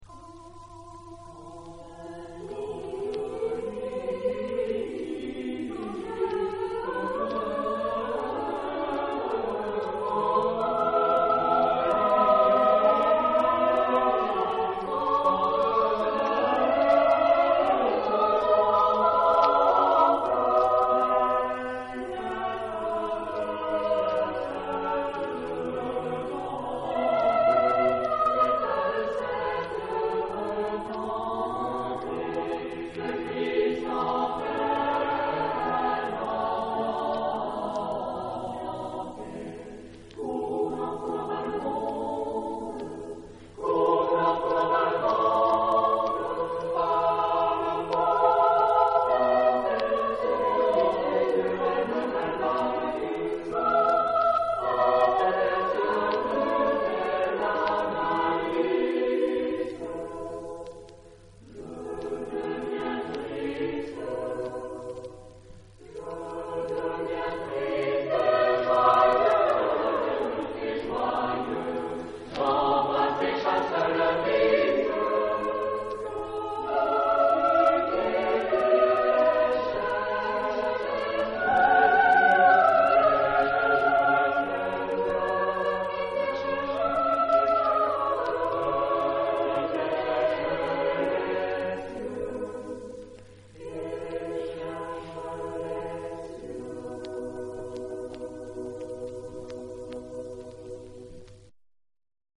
Género/Estilo/Forma: Renacimiento ; Profano ; Canción
Tipo de formación coral: SATB  (4 voces Coro mixto )